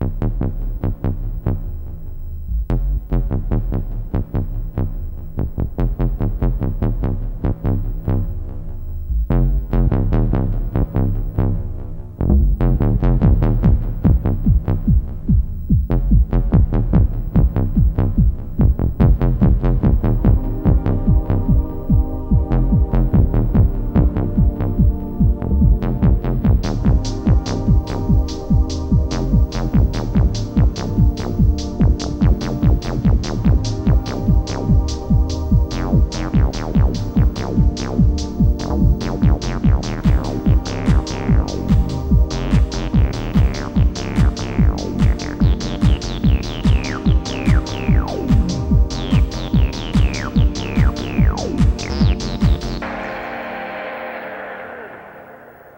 P800VintageAcid.mp3